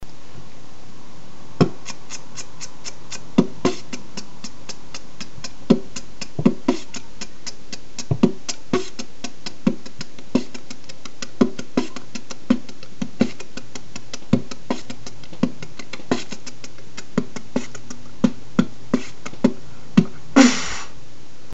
делать дома было нечего, и вот решил заняться битбоксом biggrin